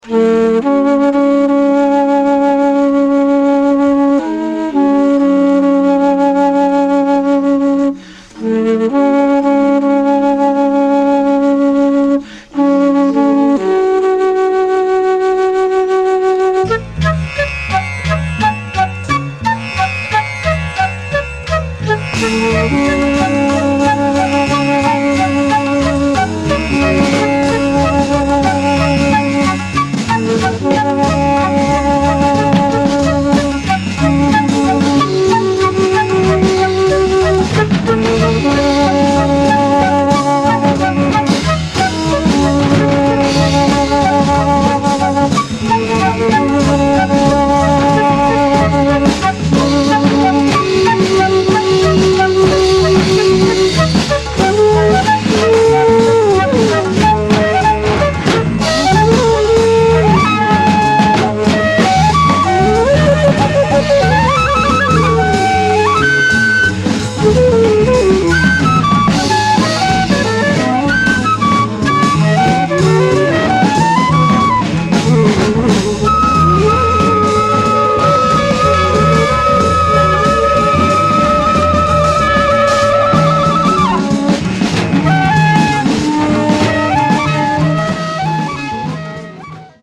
East meets West jazz